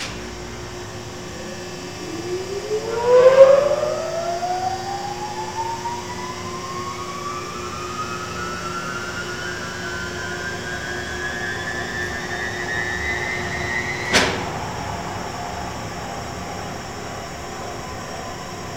TURBINE +00R.wav